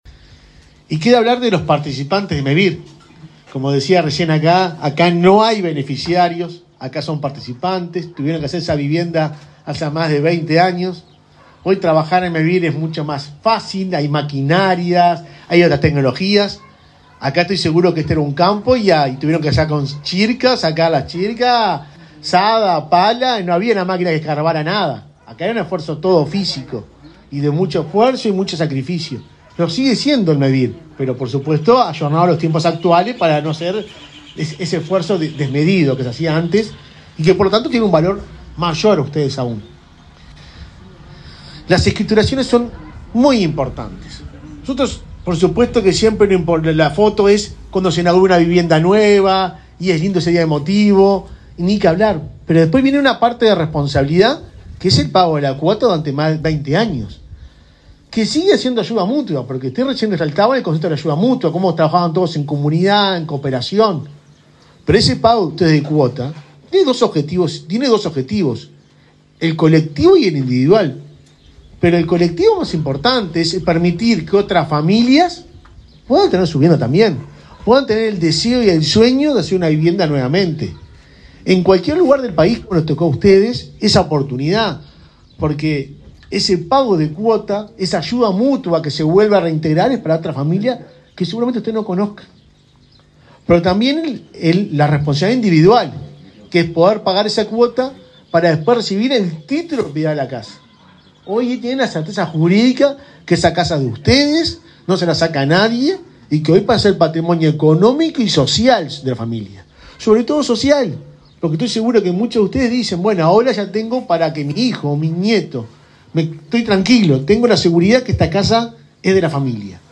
Palabras de autoridades en acto de Mevir
El presidente de Mevir, Juan Pablo Delgado, y el subsecretario de Vivienda, Tabaré Hakenbruch, participaron en un acto de escrituración de casas, en